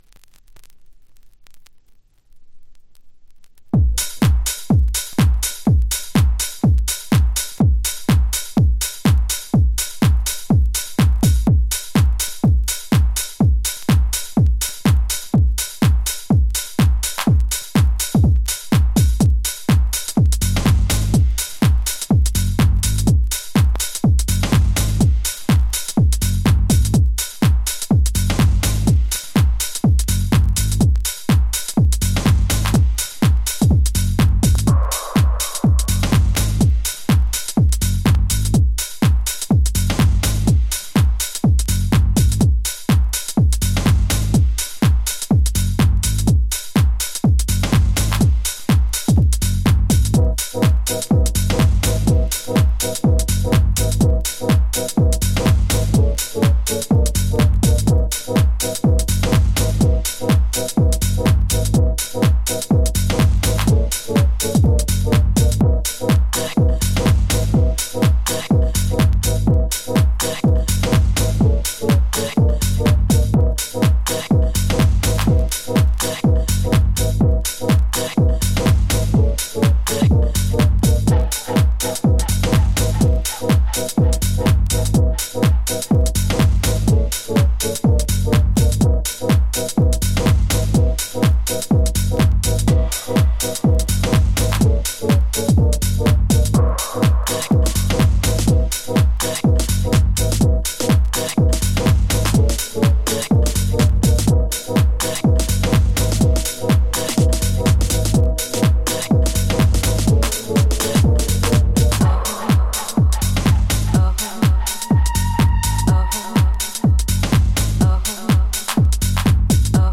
縦ノリですが、力強いスウィング感。